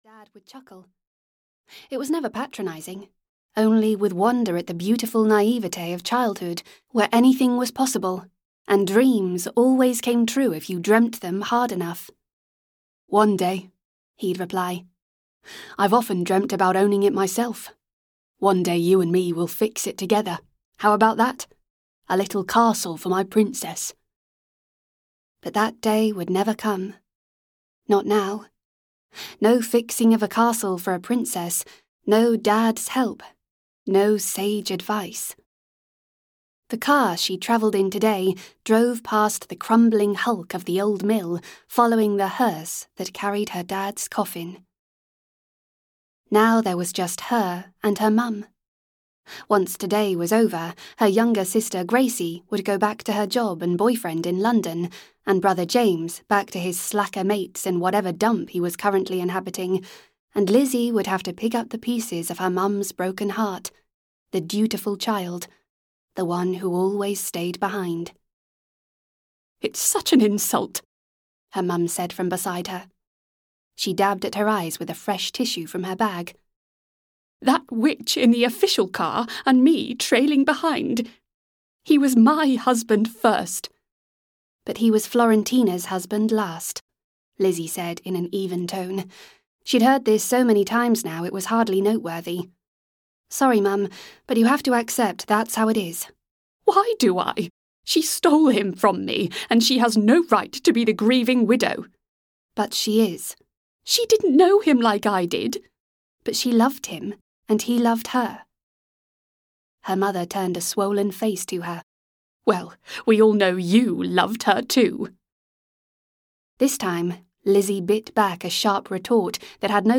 The Mill on Magnolia Lane (EN) audiokniha
Ukázka z knihy